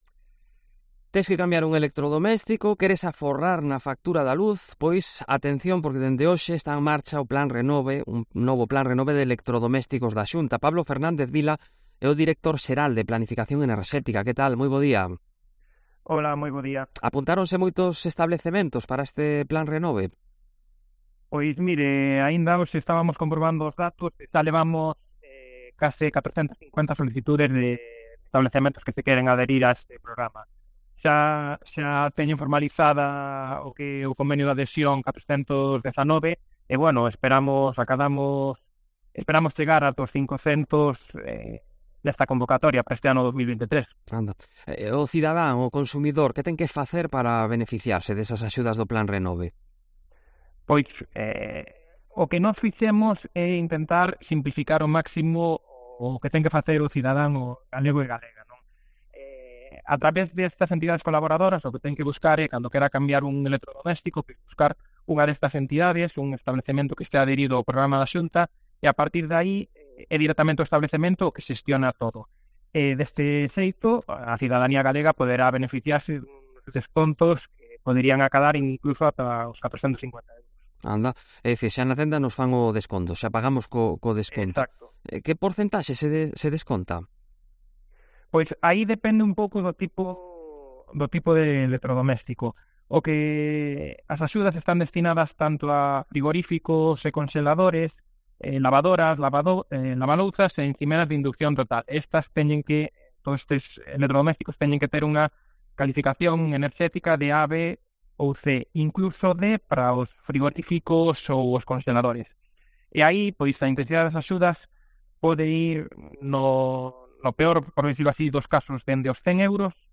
El plan renove de electrodomésticos, con Pablo Fernández Vila, director de Planificación Enerxética